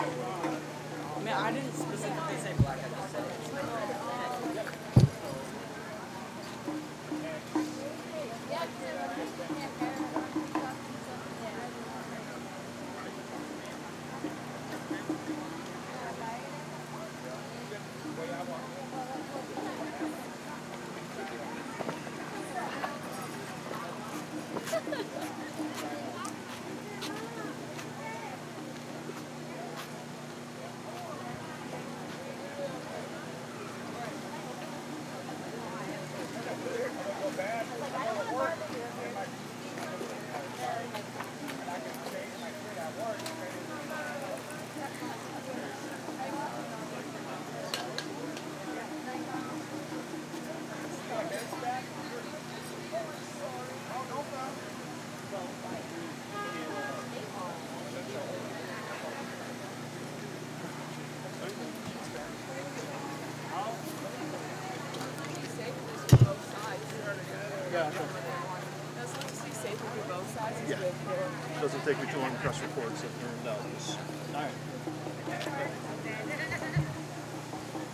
Menominee POWWOW